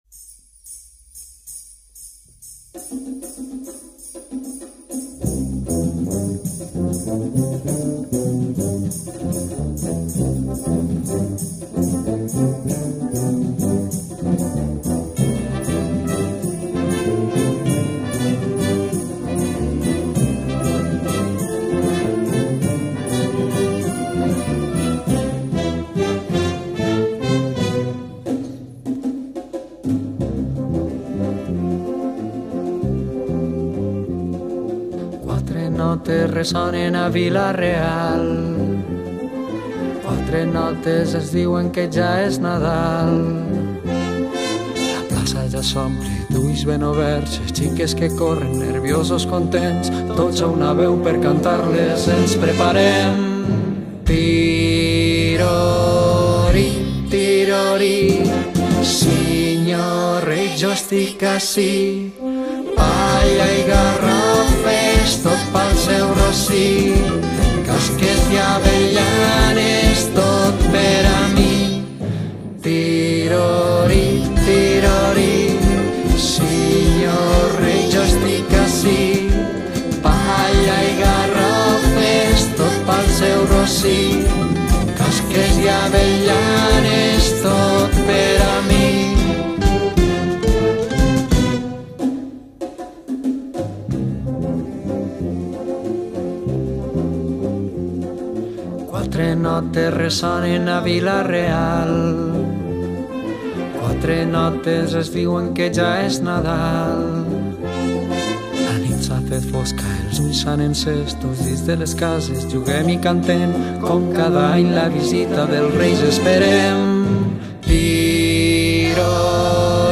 El tradicional ‘Tirorí’ interpretat per la Banda Jove de la Unió Musical La Lira de Vila -real y el grup alcoià VerdCel